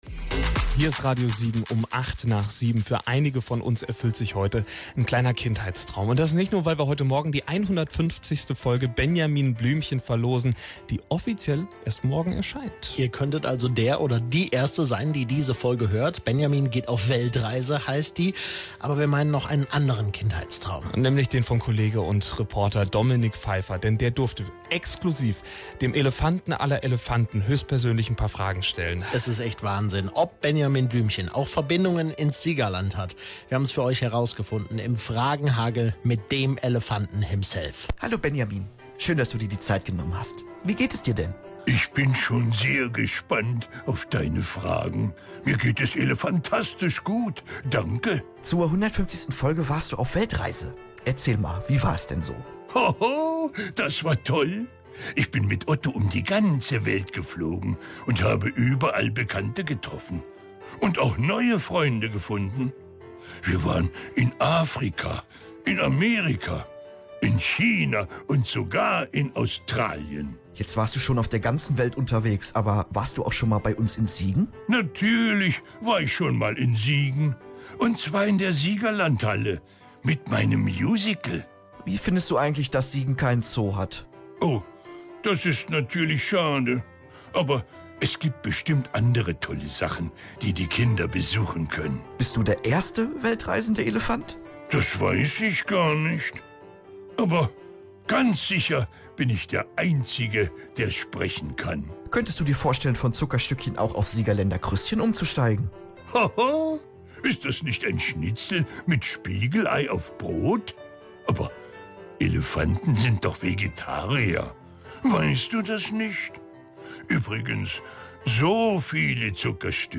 Exklusivinterview mit Benjamin Blümchen